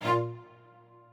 strings6_47.ogg